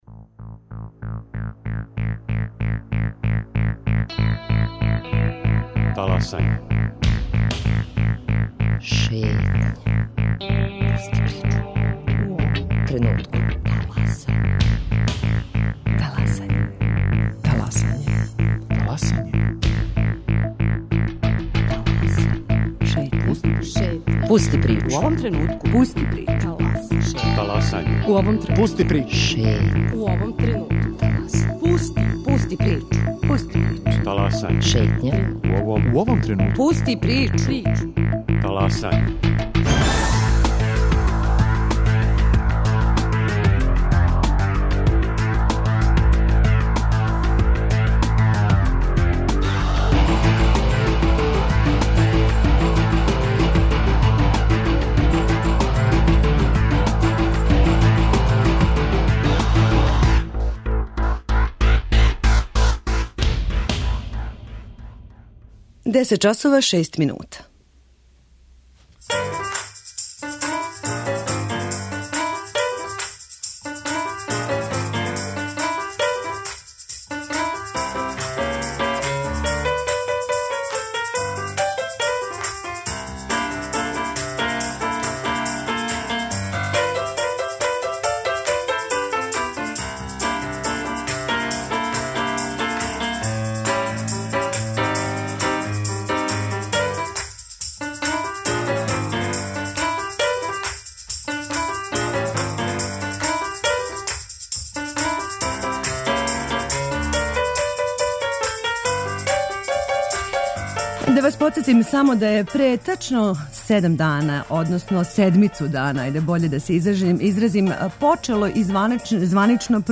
Радио Београд 1, 10.00